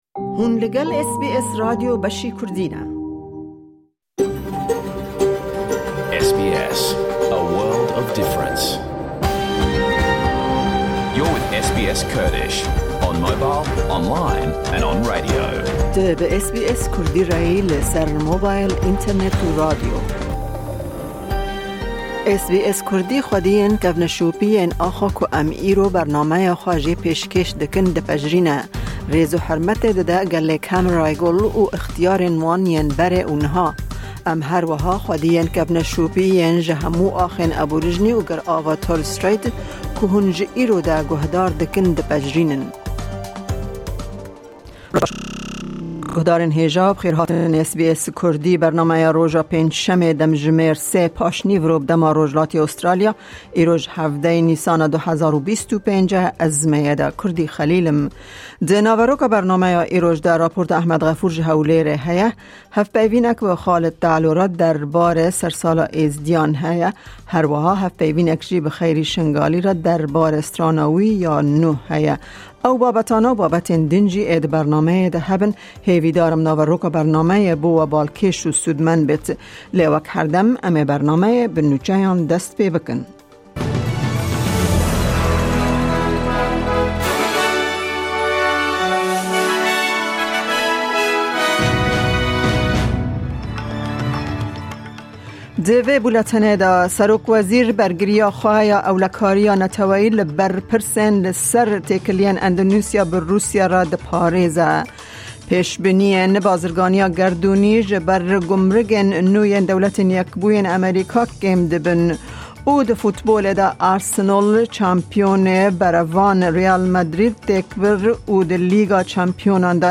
Naveroka vê bernameyê Nûçe, raport, hevpeyvîn û gelek babetên din pêk dihêt.